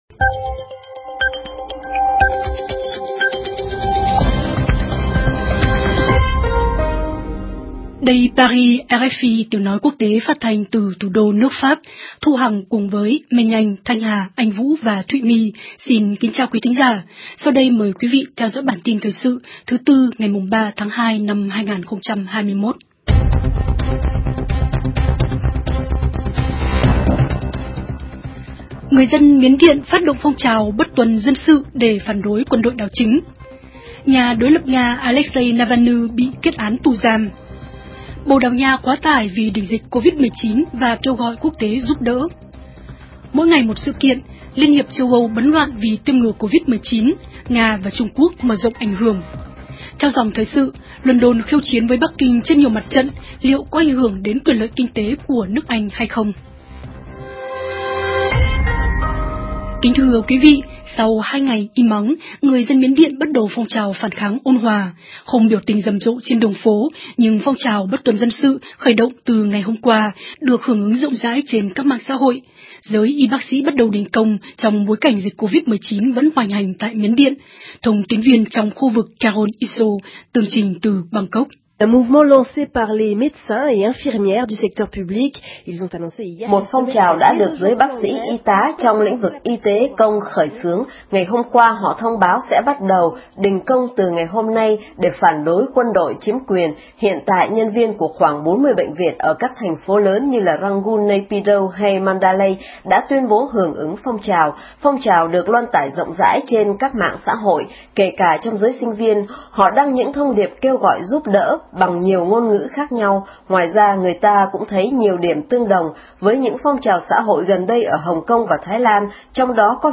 Chương trình phát thanh hàng ngày của RFI Tiếng Việt được truyền trực tiếp và lưu giữ trên YOUTUBE.